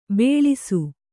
♪ bēḷisu